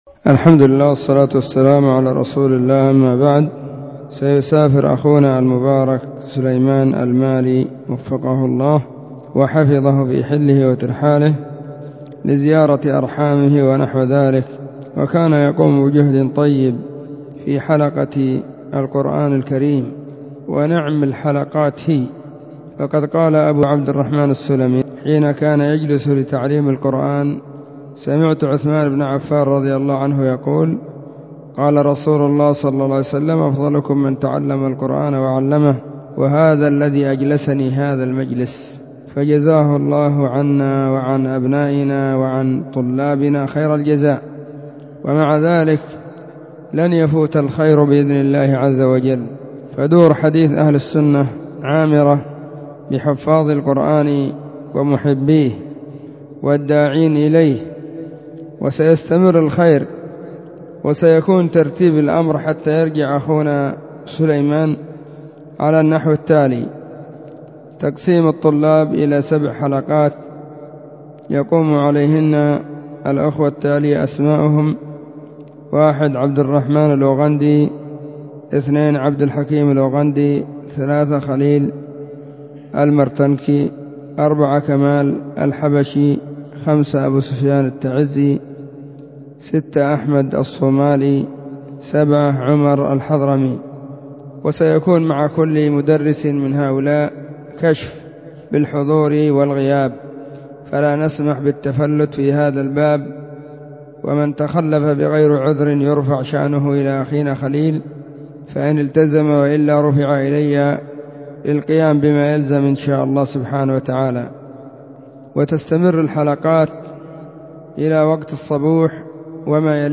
📢 مسجد الصحابة – بالغيضة – المهرة، اليمن حرسها الله.
السبت 28 جمادى الآخرة 1441 هــــ | كلمــــات | شارك بتعليقك